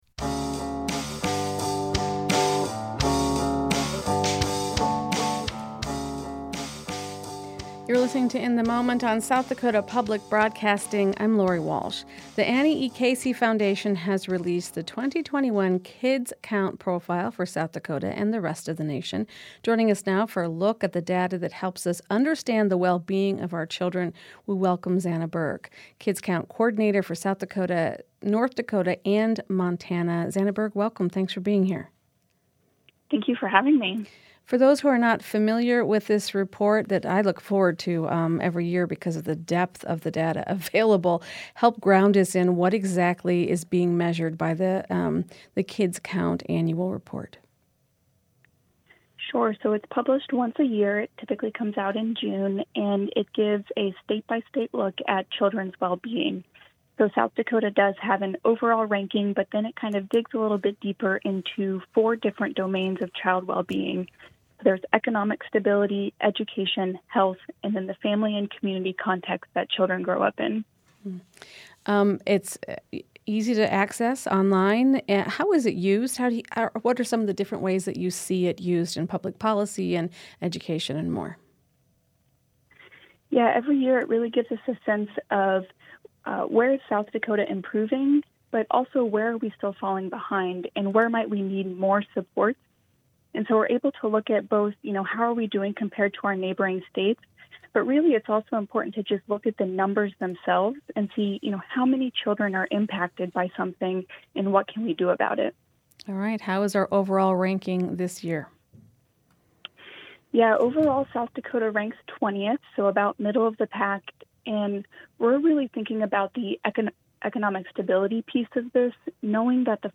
This story comes from a recent interview on SDPB's weekday radio program, " In the Moment ."